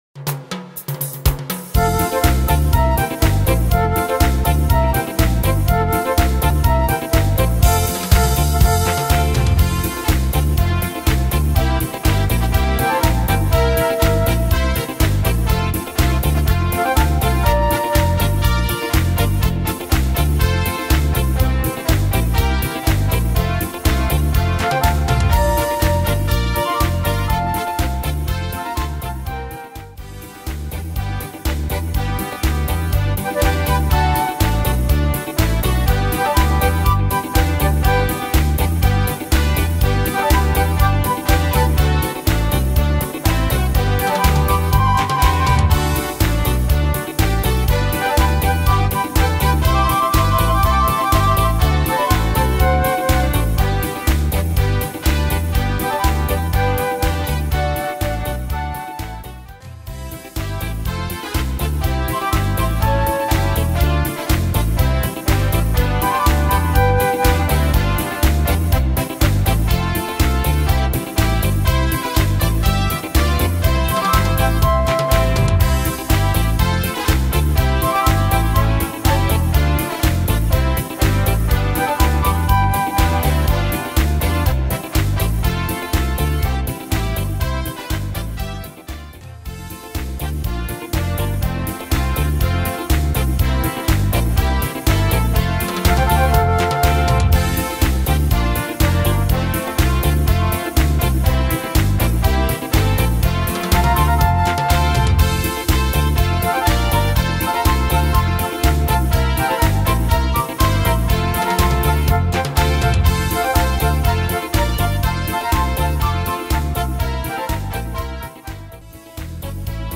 Tempo: 122 / Tonart: F-Dur